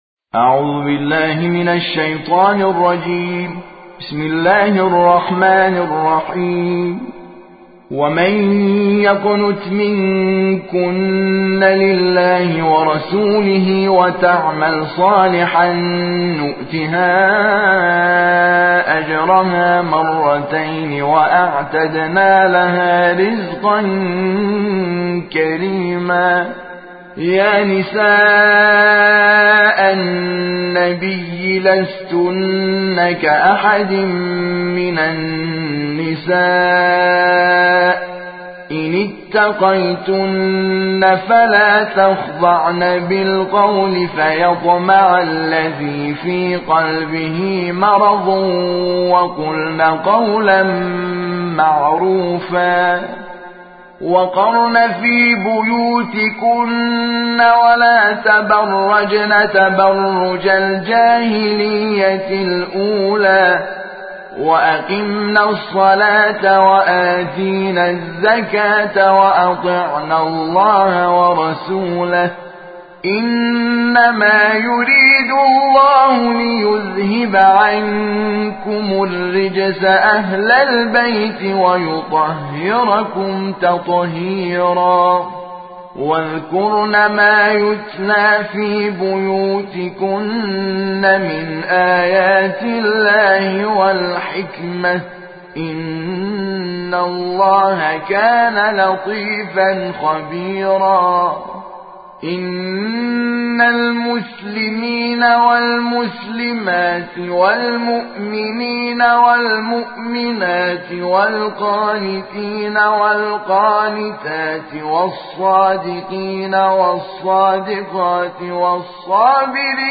ترتیل جزء سی ام